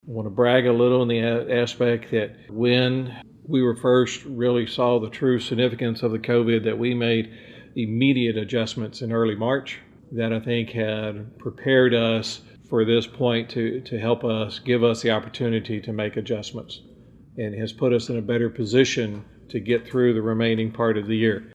City Manager James Fisher presented the City Council at its meeting today (Thursday) with an update on the budget for the current fiscal year stating that, due to shutdowns of both Texas and Brenham, the city has lost an estimated $1,058,656 in revenue.